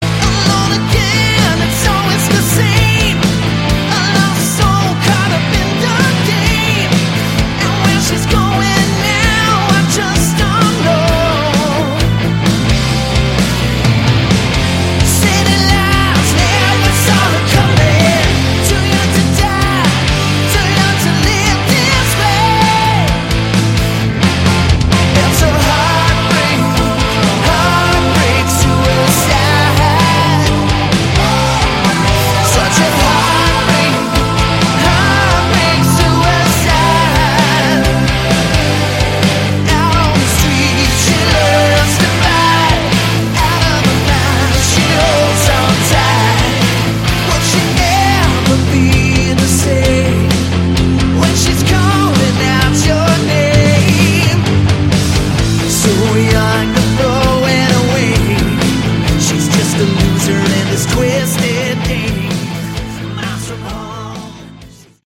Category: Hard Rock
bass, keyboards
rhythm guitars, keyboards, backing vocals
lead and backing vocals
drums, backing vocals
lead guitar